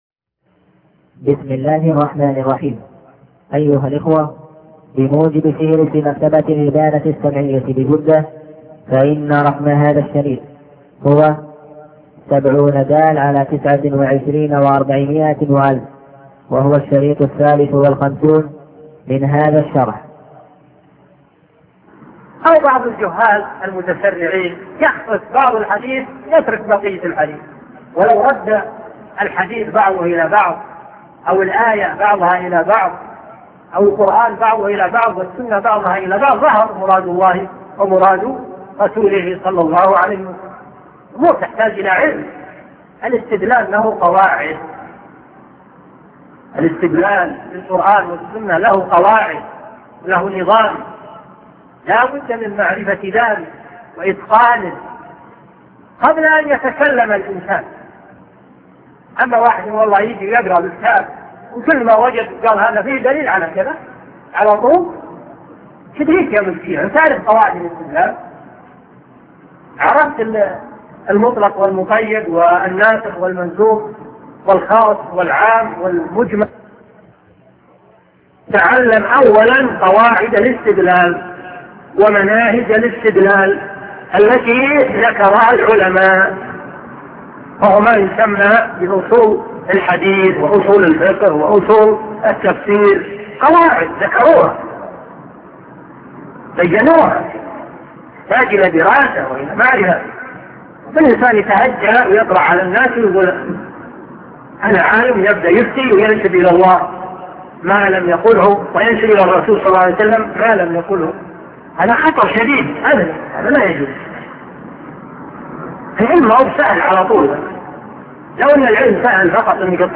قراءة الطالب للمتن .